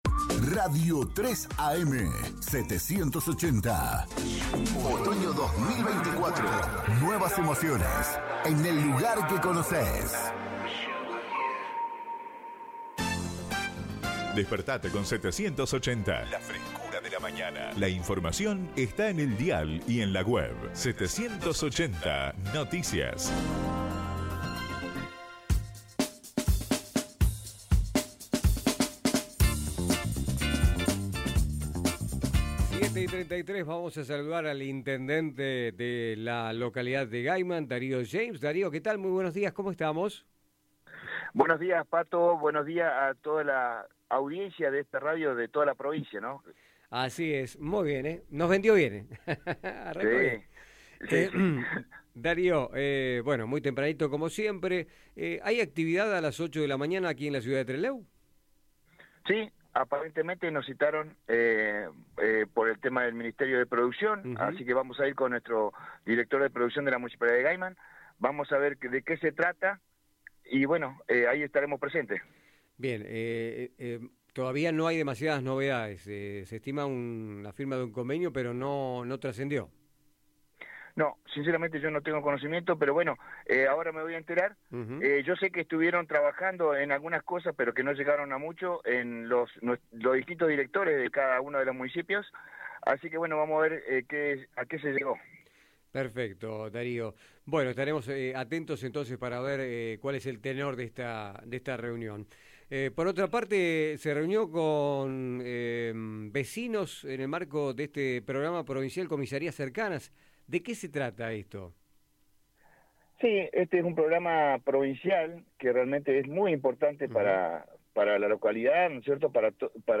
El intendente de Gaiman, Darío James, comparte en entrevista con Radio 3 los avances en seguridad, infraestructura y turismo en la localidad,